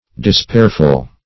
Despairful \De*spair"ful\, a.